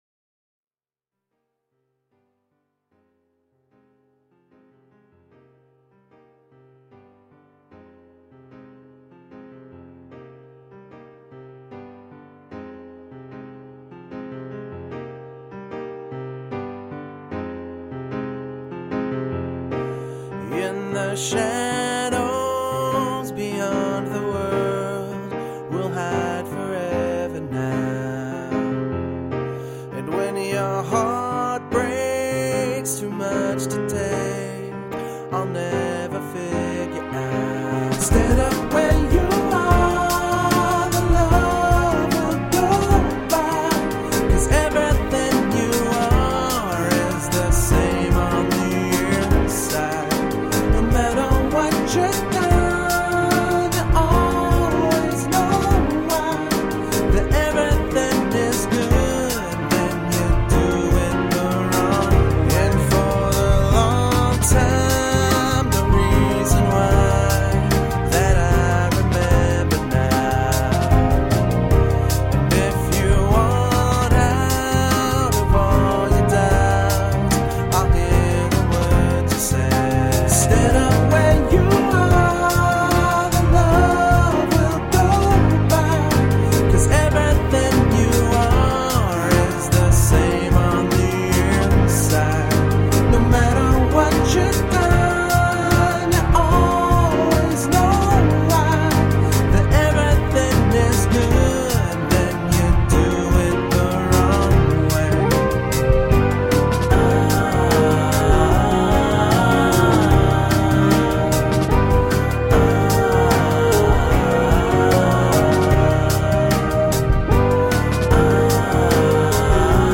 Soulful electro pop.
Tagged as: Electro Rock, Rock, Electro Pop